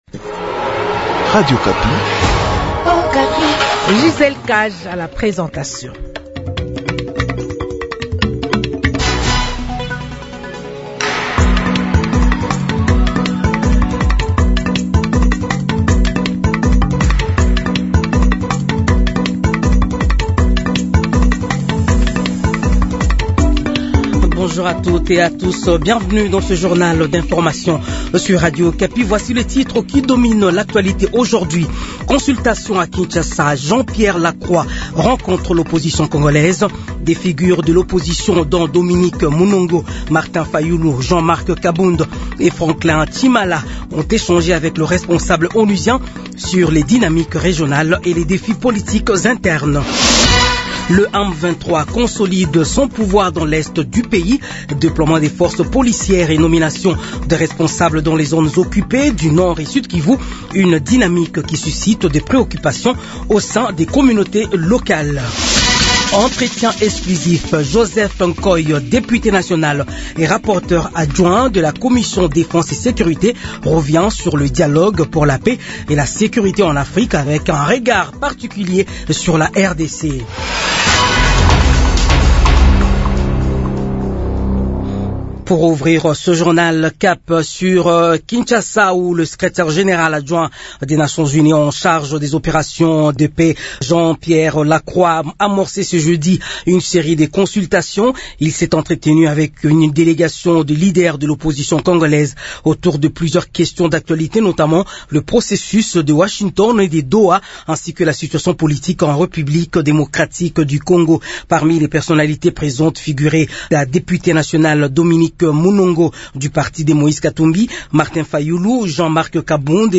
Journal 15h